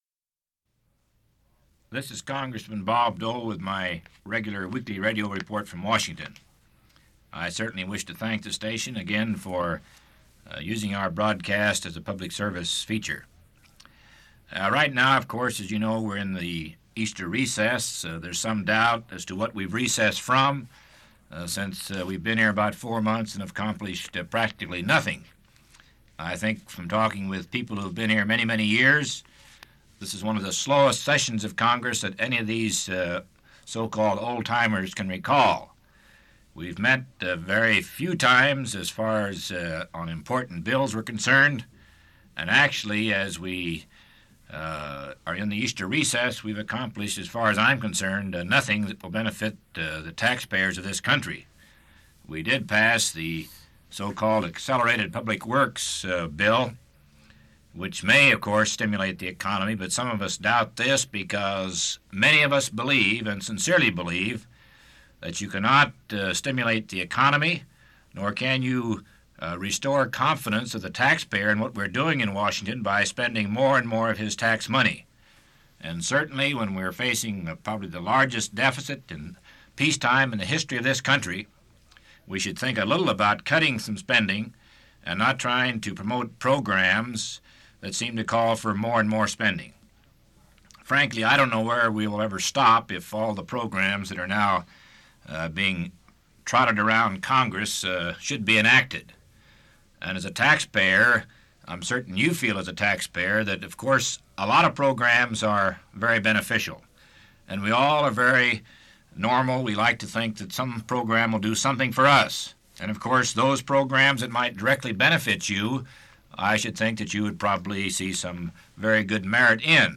Part of Weekly Radio Report: Deficit Spending & the Wheat Referendum